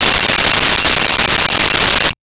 Cell Phone Ring 01
Cell Phone Ring 01.wav